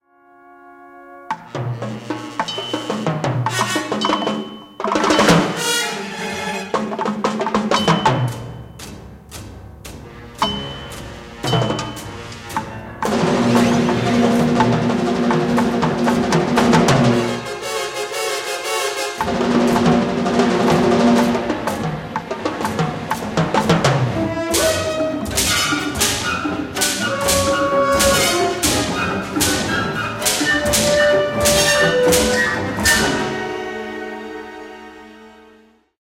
Genre Film score